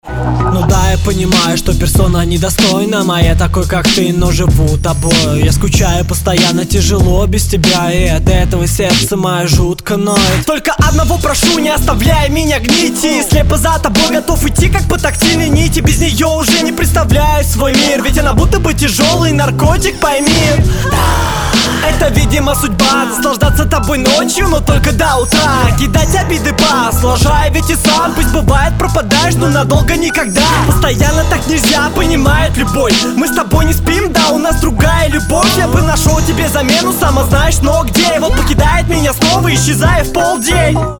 В тексте ничего примечательного, читка средняя